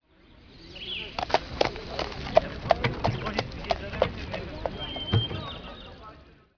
Ambient
1 channel
city7.wav